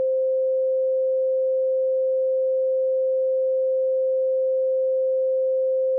mp3_music_loop_sample.mp3